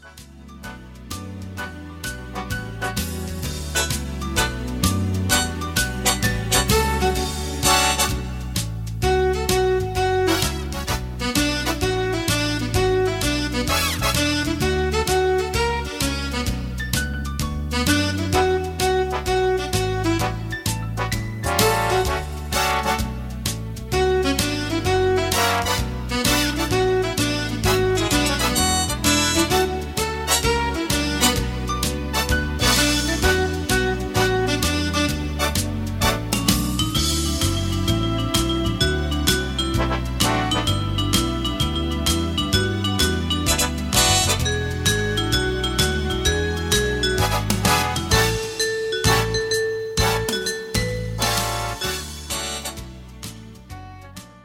Instrumental
Singing Calls
Big Band